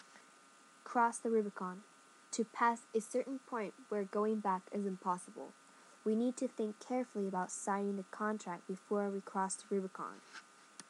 英語ネイティブによる発音は下記のURLから聞くことができます。